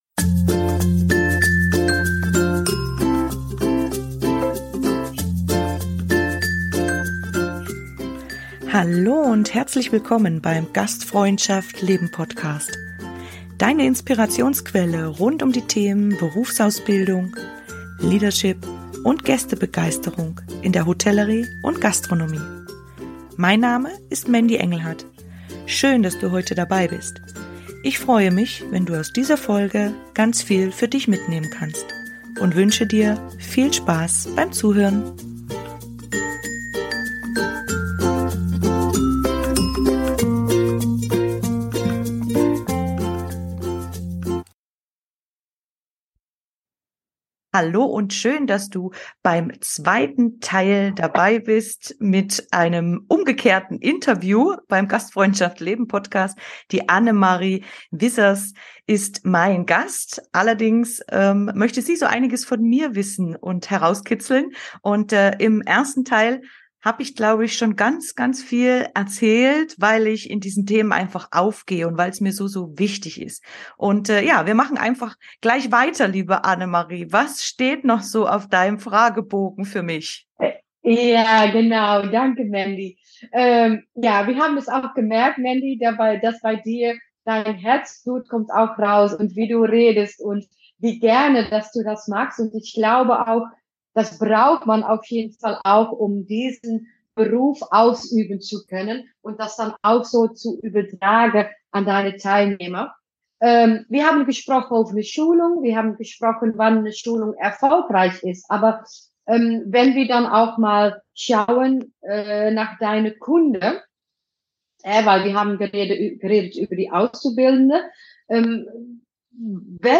In diesen drei Folgen darf ich zu Gast in meinem eigenen Podcast sein und du wirst einiges über mich persönlich, mein Wirken und meine Herzensthemen erfahren.